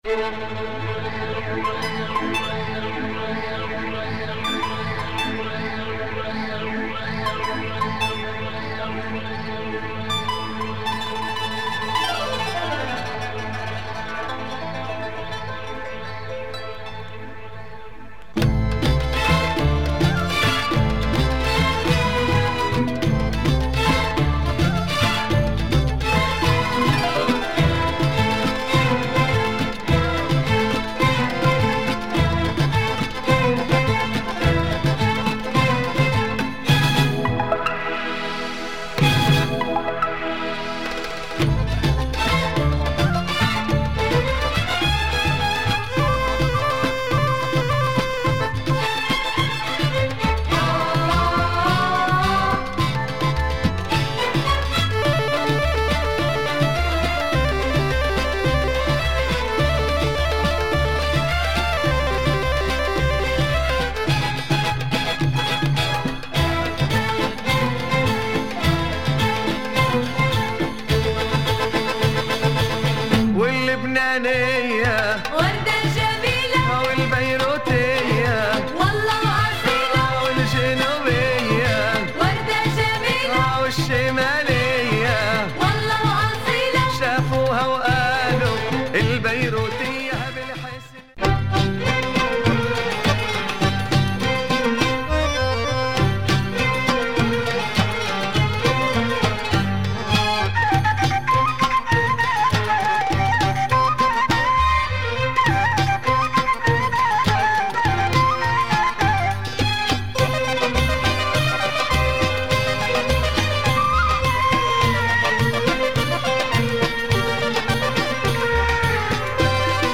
80's Lebanese pop, full of samples and middle eastern beats.